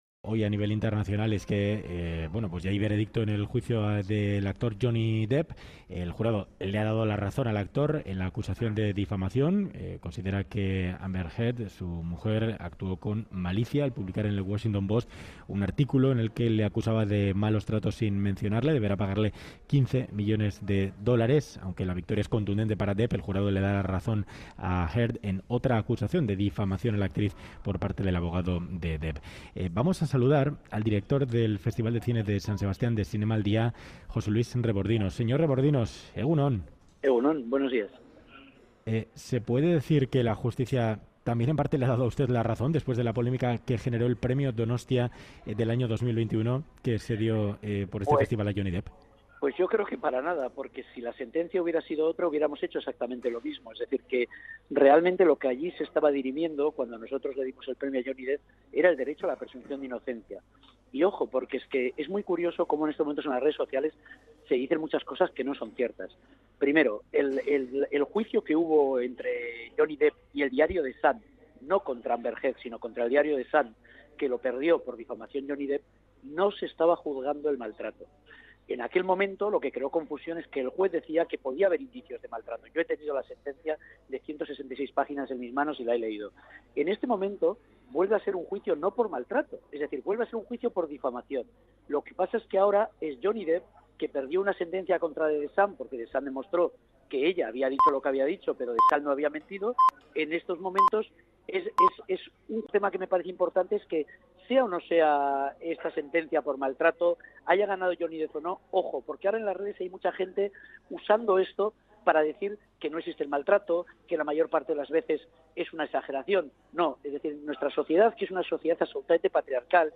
Radio Euskadi ENTREVISTA